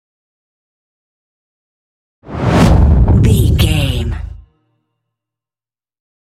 Dramatic whoosh to hit deep trailer
Sound Effects
Atonal
dark
intense
tension
woosh to hit